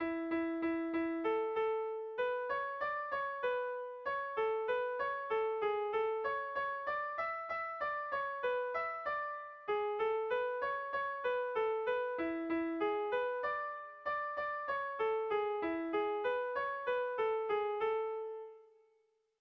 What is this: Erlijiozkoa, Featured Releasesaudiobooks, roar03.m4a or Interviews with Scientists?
Erlijiozkoa